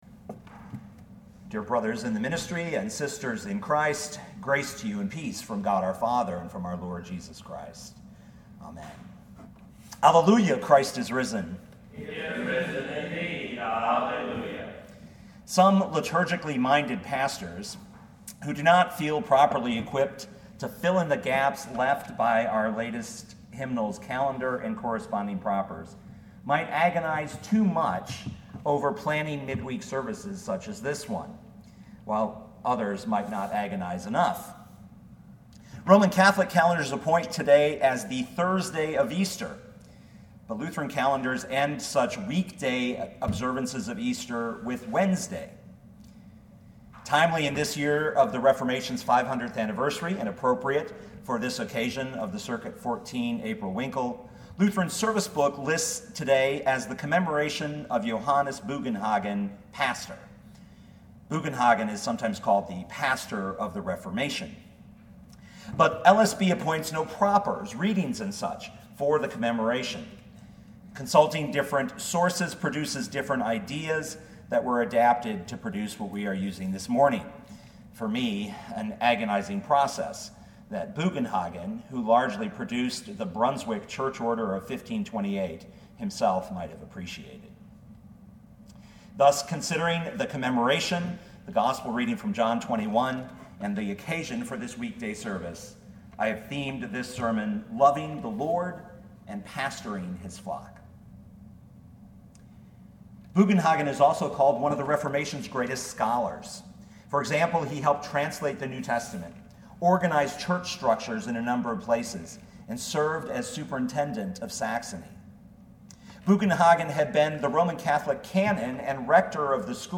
Sermons
Commemoration of Johannes Bugenhagen, Pastor, April 20, 2017